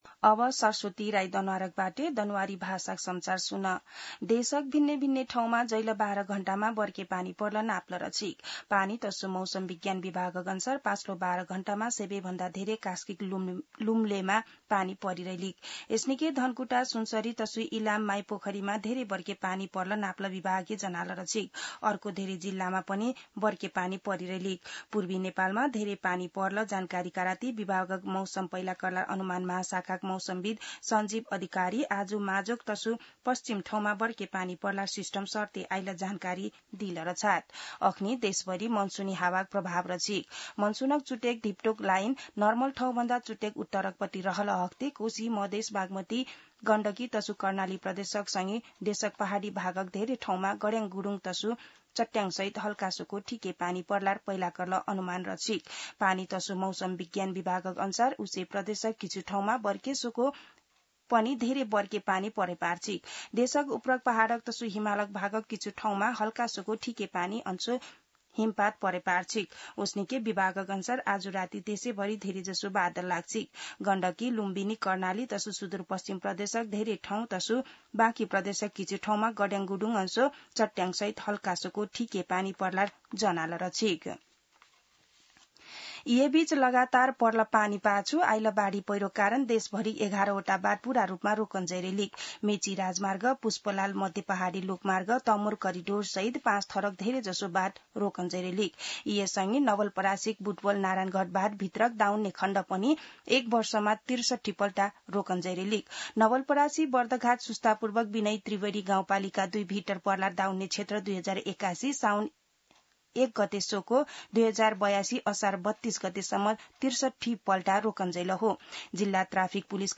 दनुवार भाषामा समाचार : ४ साउन , २०८२